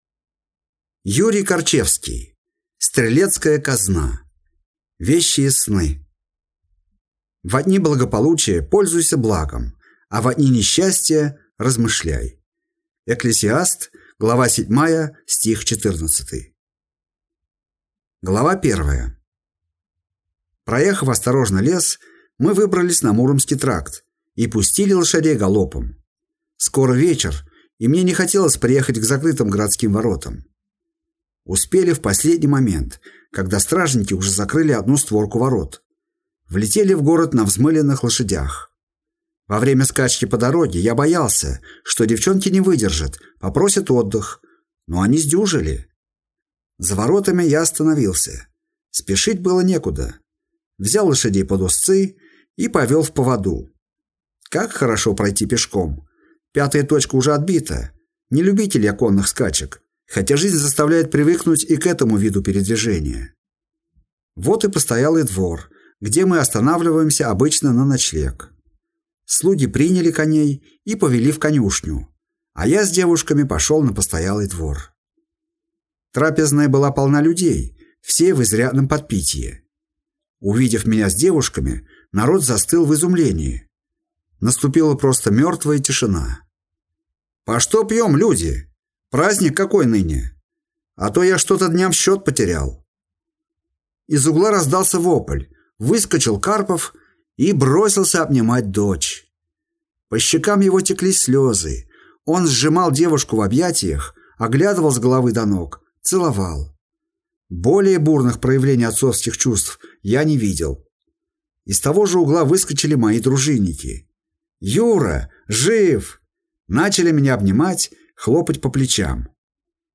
Аудиокнига Стрелецкая казна. Вещие сны | Библиотека аудиокниг
Прослушать и бесплатно скачать фрагмент аудиокниги